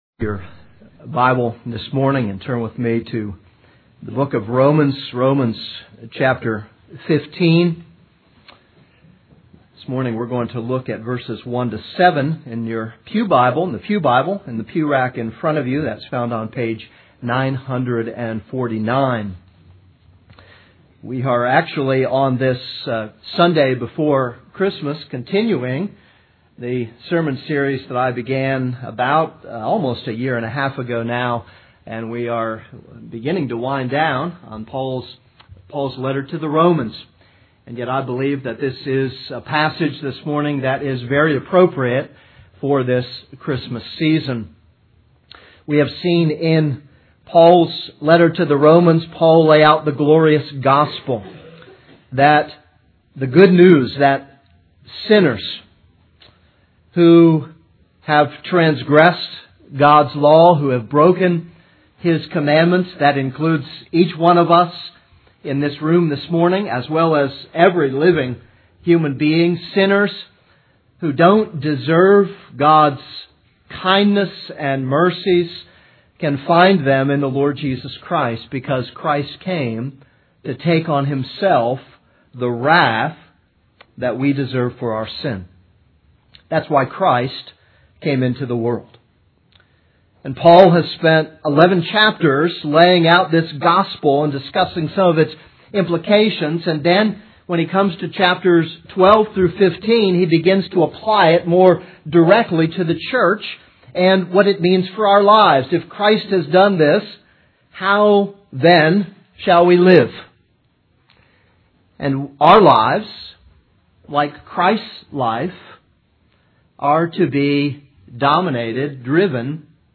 This is a sermon on Romans 15:1-7.